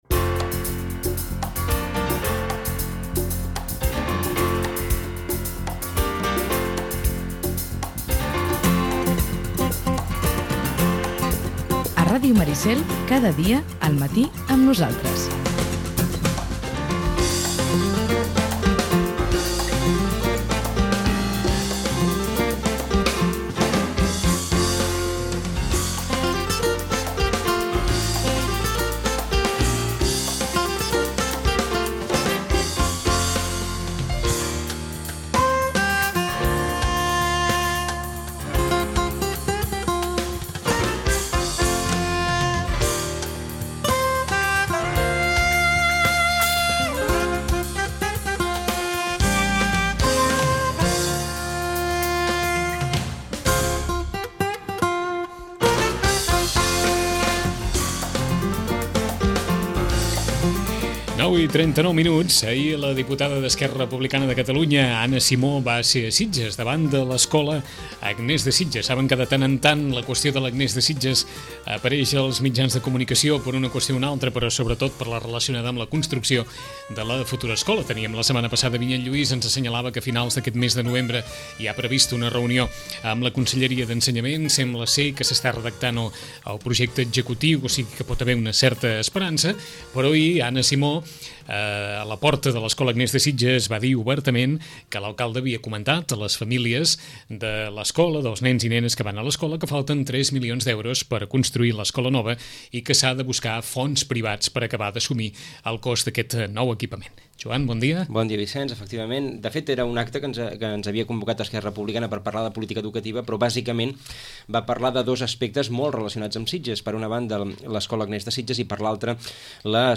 En roda de premsa, la diputada d’ERC Anna Simó afirmà ahir que l’alcalde ha comentat a les famílies de l’escola Agnès de Sitges, que només hi ha compromesos 2 milions d’euros per a la construcció del nou centre, i que els 3 milions que falten hauran d’aconseguir-se a través de finançament privat. Simó també es referí a l’augment de les quotes de l’escola de música, per la disminució de l’aportació de la Generalitat.